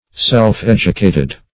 Meaning of self-educated. self-educated synonyms, pronunciation, spelling and more from Free Dictionary.